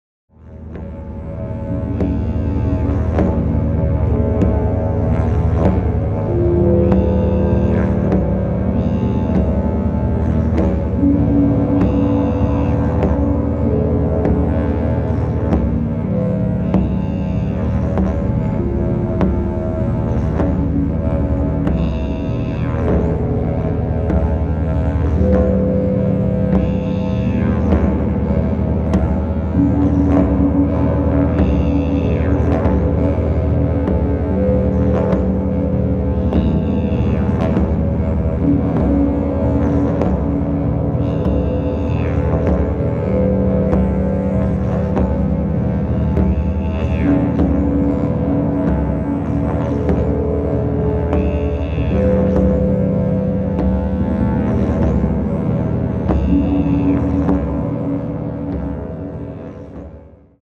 Eine musikalische Vertonung der fünf Elemente
Diese CD besitzt sehr tiefe und sehr hohe Töne.